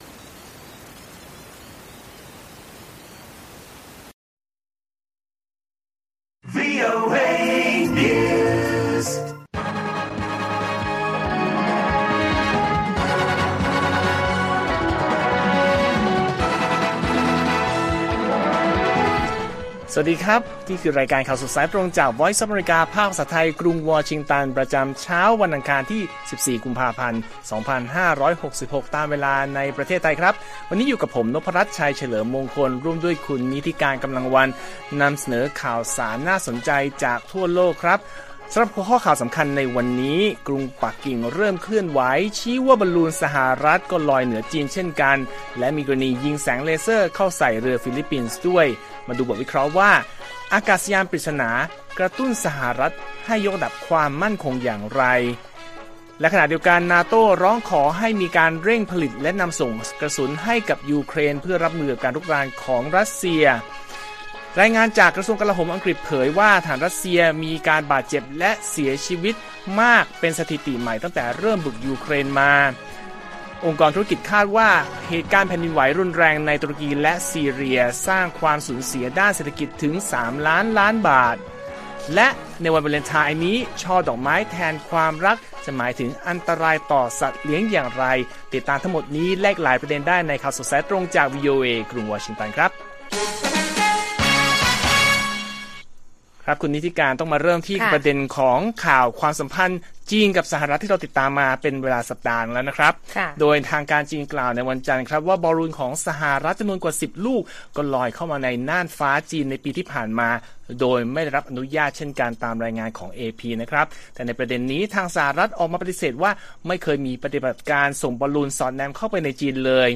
ข่าวสดสายตรงจากวีโอเอ ไทย อังคาร 14 กุมภาพันธ์ 2566